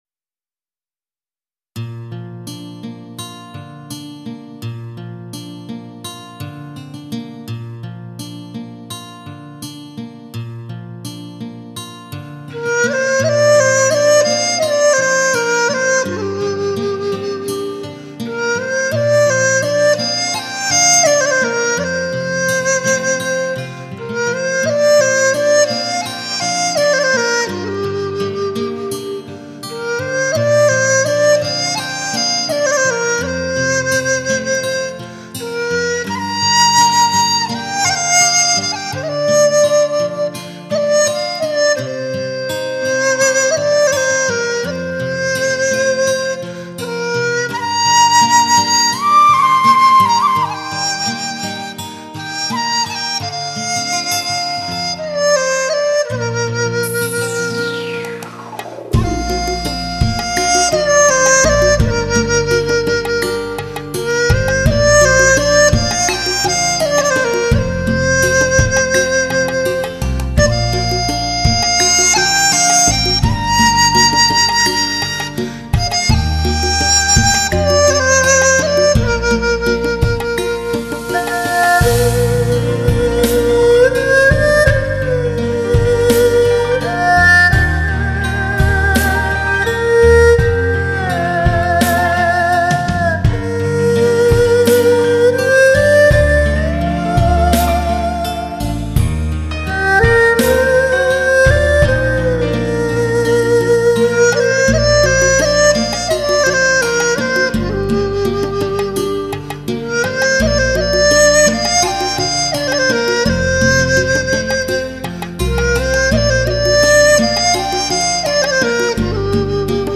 中国特色的古典与流行“跨界示范”之作
更在编曲之中加入大量的打击乐器，为12首耳熟能详的经典流行金曲，换上全新的印象。
优雅的长笛，伴着敲击乐器，就这样轻轻地带出一点点蓝色的忧郁。